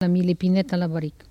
Patois